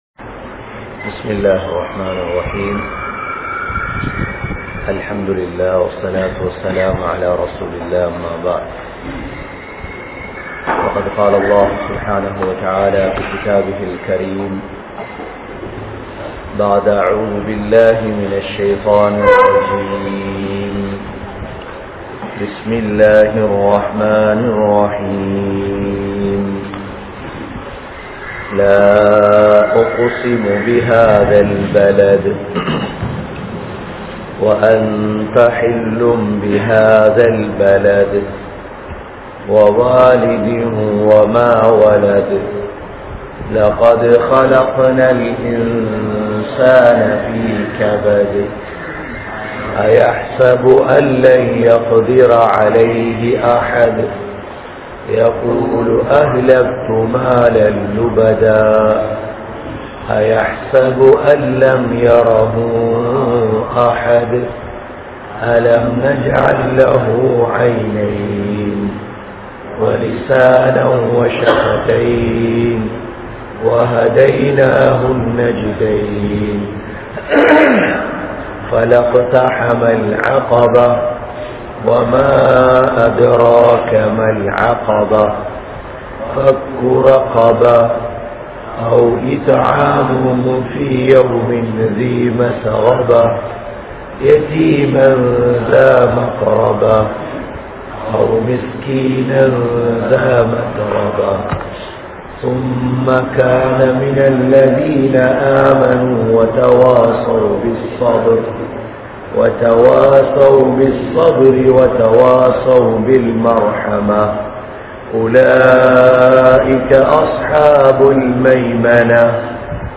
Surah Al Balad(Thafseer) | Audio Bayans | All Ceylon Muslim Youth Community | Addalaichenai
Aluthgama, Dharga Town, Meera Masjith(Therupalli)